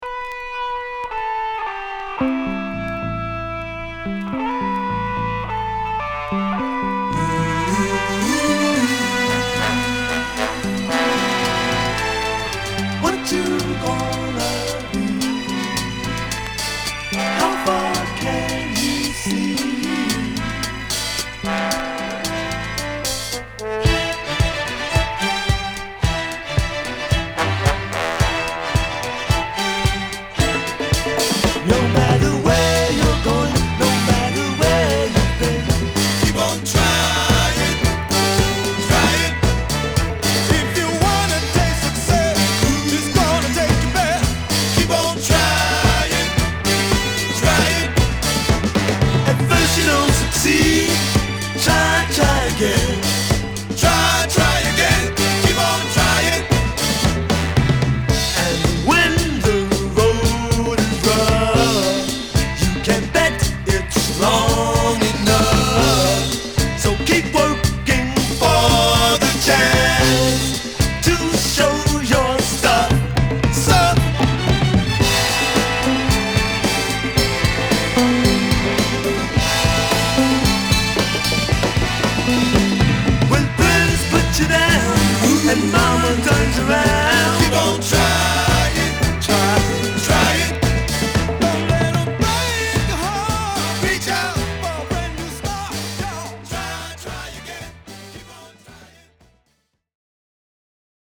Good NY Latin Disco~Funk!!